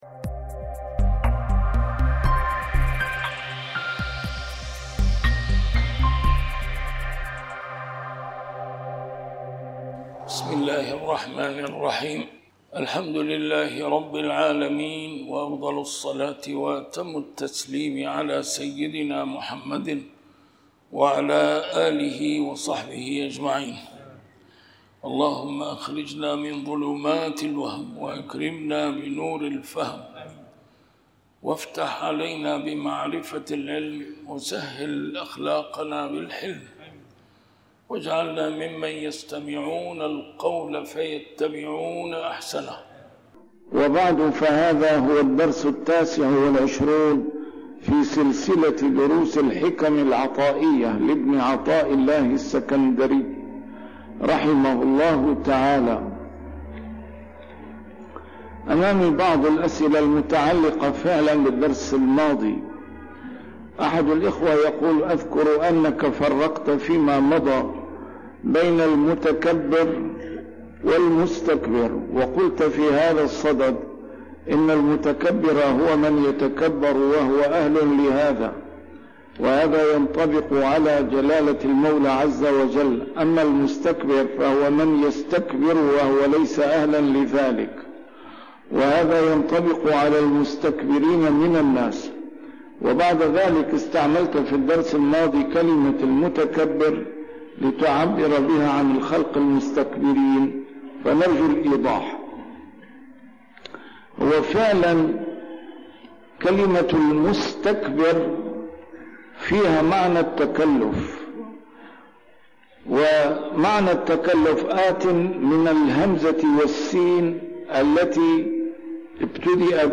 A MARTYR SCHOLAR: IMAM MUHAMMAD SAEED RAMADAN AL-BOUTI - الدروس العلمية - شرح الحكم العطائية - الدرس رقم 29 شرح الحكمة 16